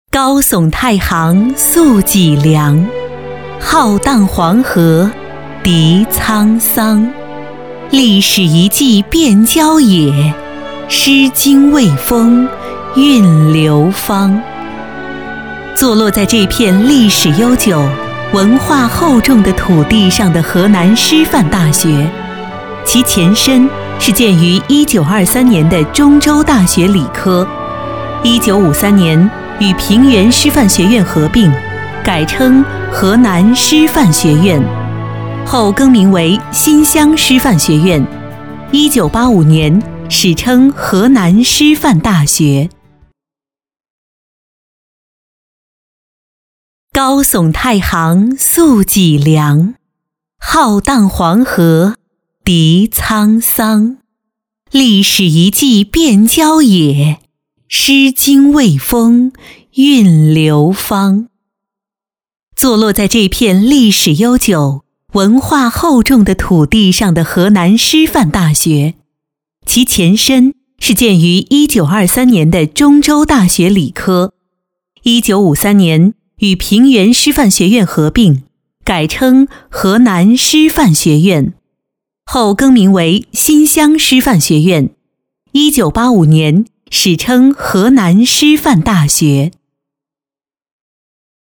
国语青年大气浑厚磁性 、沉稳 、亲切甜美 、女专题片 、宣传片 、旅游导览 、80元/分钟女S143 国语 女声 专题片-工作汇报总结-自然 大气浑厚磁性|沉稳|亲切甜美